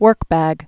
(wûrkbăg)